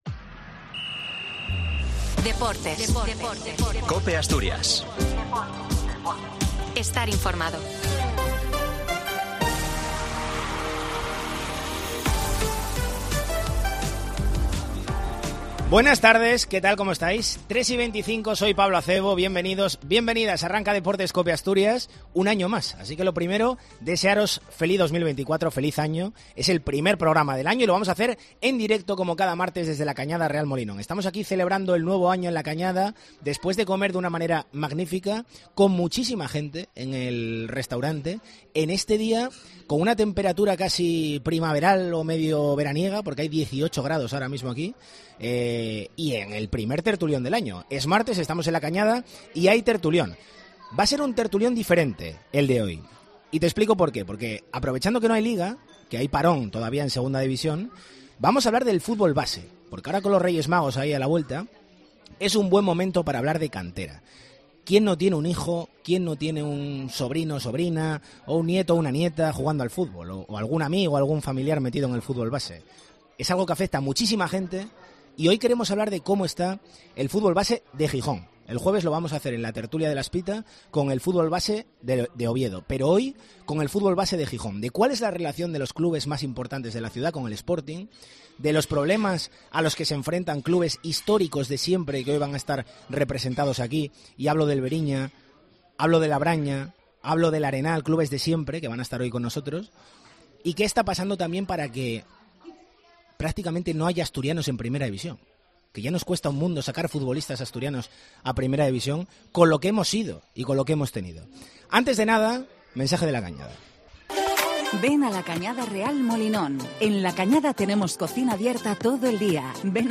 'El Tertulión del fútbol base' en COPE Asturias Con el Sporting regresando paulatinamente a la actividad tras el parón navideño y con el mercado de fichajes recién abierto, el fútbol base ha adquirido un papel protagonista en el primer programa de 2024 en Deportes COPE Asturias . A las puertas de la llegada de lo Reyes Magos , en COPE hemos hablado de la salud del fútbol de cantera en Gijón.